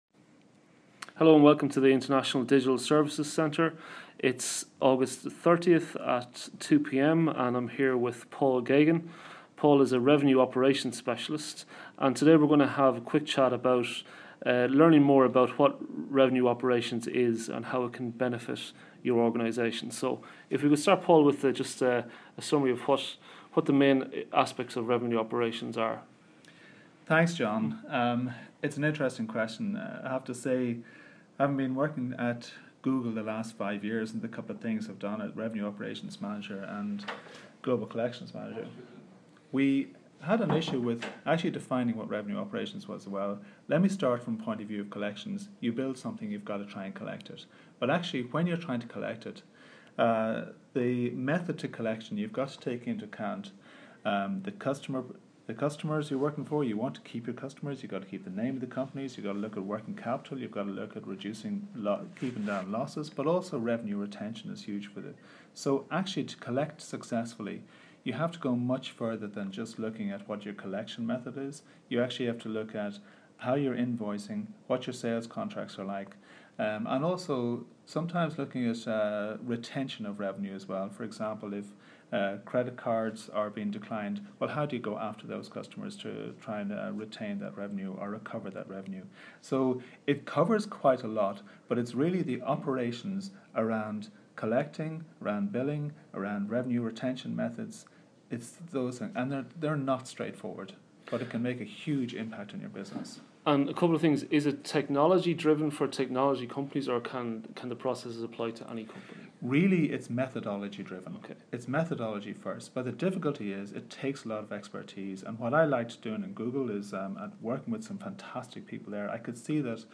recorded at our Dublin Headquarters in Eastpoint Business Park